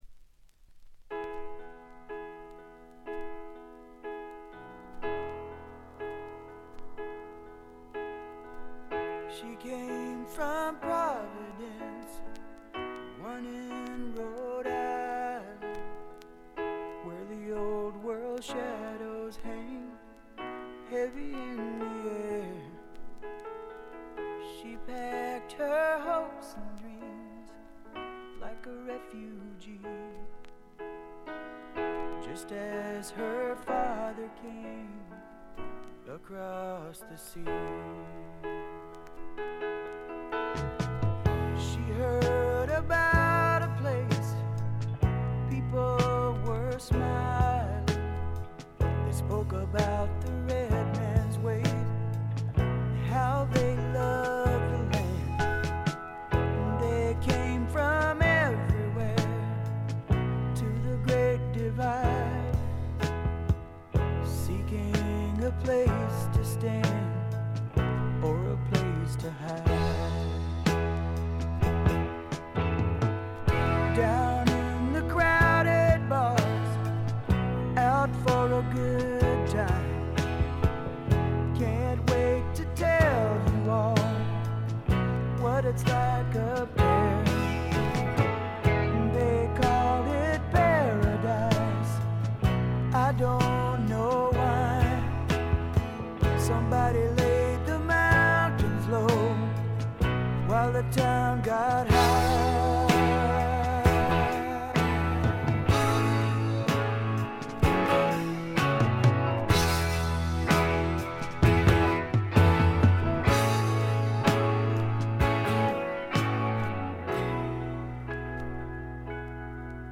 静音部で軽微なバックグラウンドノイズ、チリプチ。
試聴曲は現品からの取り込み音源です。